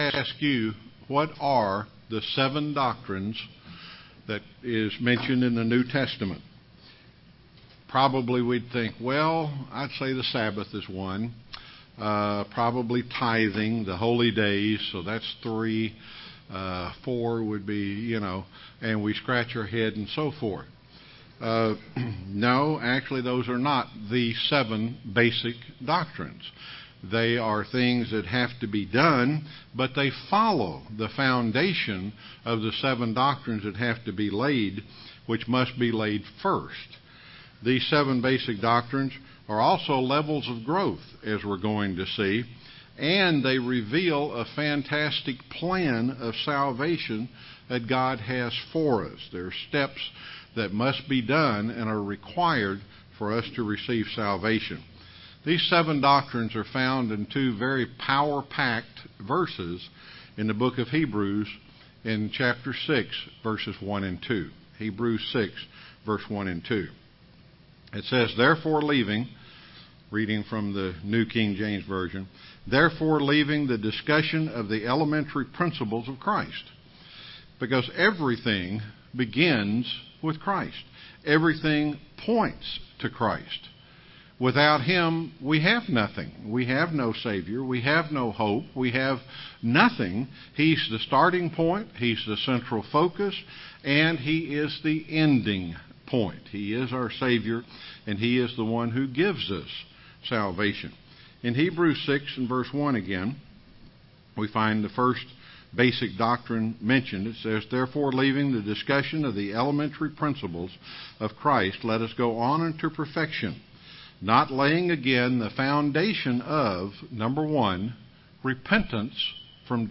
Print A look at each of the basic doctrines outlined in Heb.6 UCG Sermon Studying the bible?
Given in Rome, GA